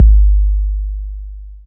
Standard 808 (JW2).wav